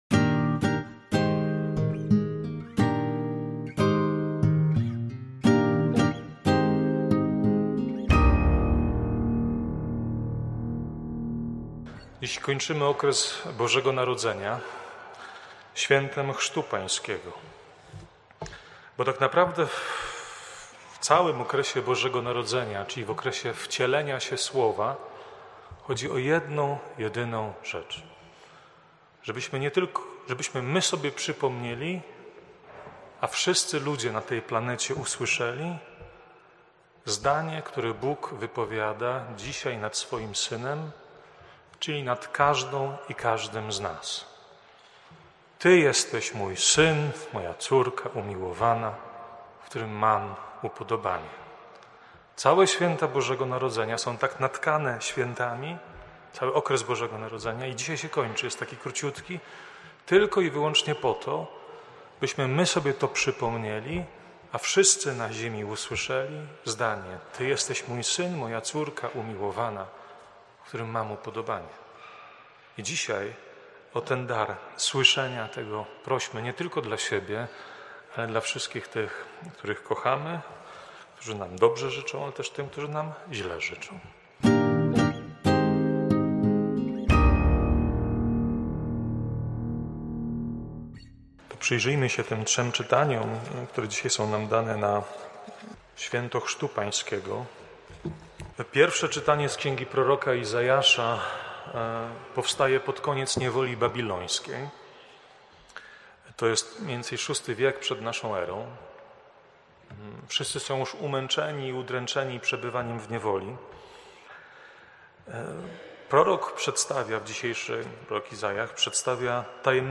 kazania.
wprowadzenie do Liturgii, oraz kazanie: